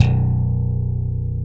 Index of /90_sSampleCDs/East Collexion - Bass S3000/Partition A/SLAP BASS-D